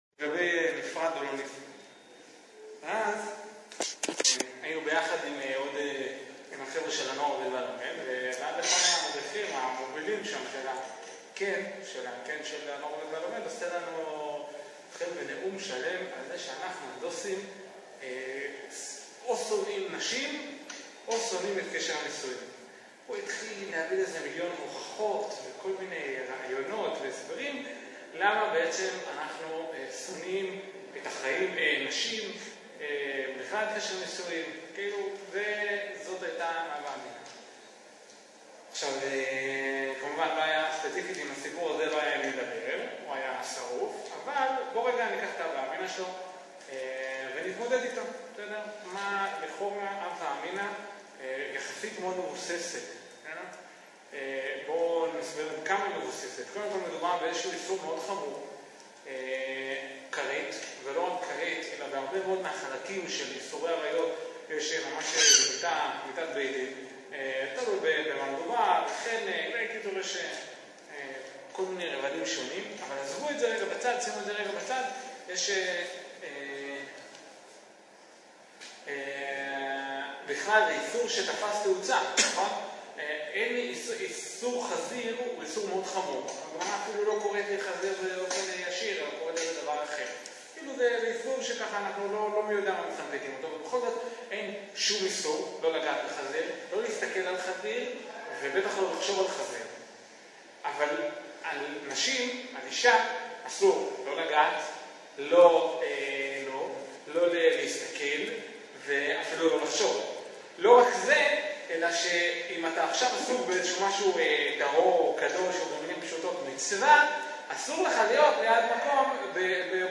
דף הבית » מאגר שיעורים תורני » אמונה מחשבה ומוסר » אמונה » עריות 1